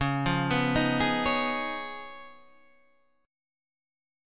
C# 9th